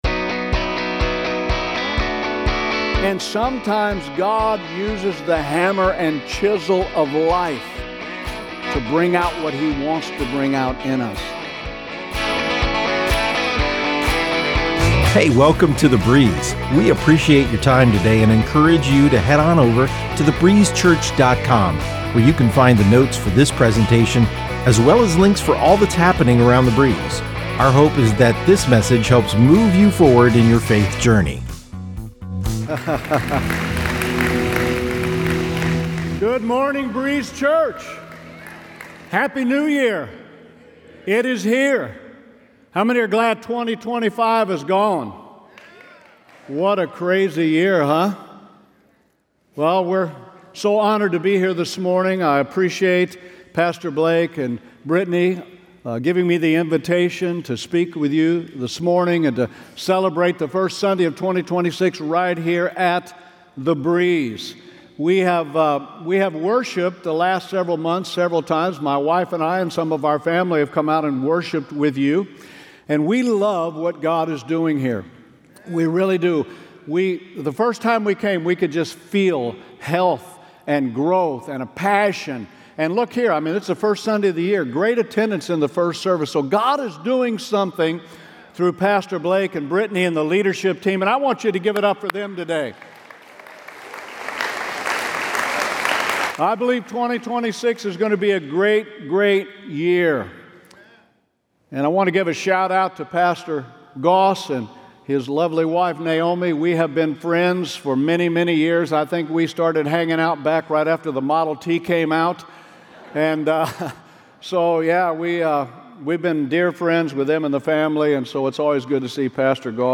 This morning we have a guest speaker